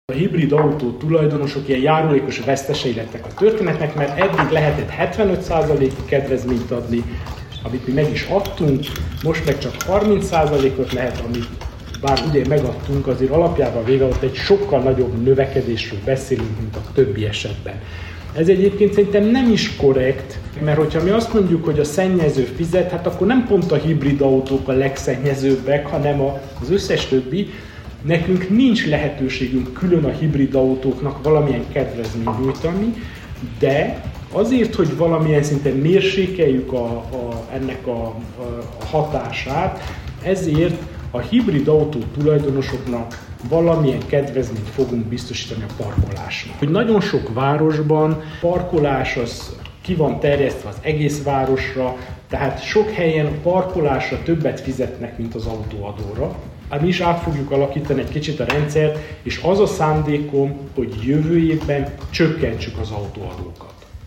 Sepsiszentgyörgyön idén parkolási kedvezményt kapnak a hibrid autók tulajdonosai – jelentette be Antal Árpád.
Ennek ellensúlyozására a sepsiszentgyörgyi önkormányzat idén parkolási kedvezményeket biztosít a hibrid járművek tulajdonosai számára – nyilatkozta Antal Árpád.